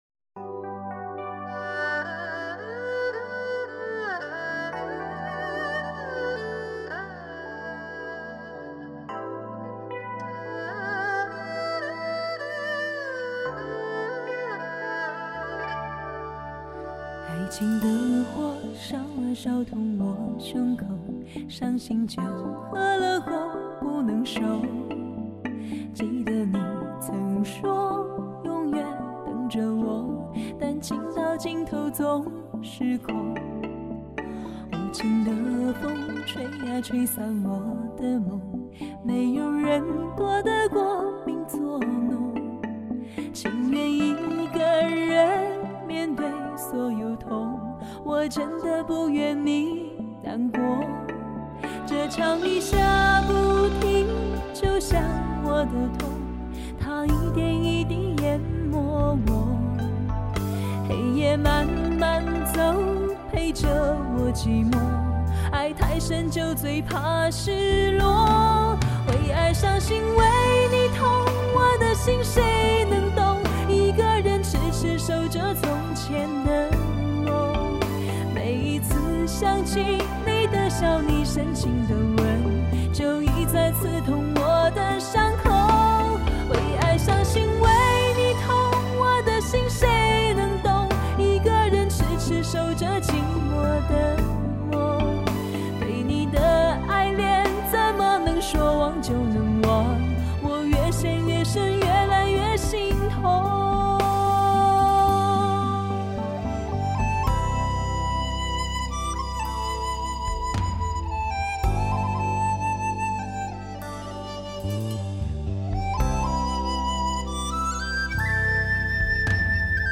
世界顶级录音设备全面满足听觉享受
天籁女声无法抗拒
是曼妙、神秘、是迷惑，让人上瘾……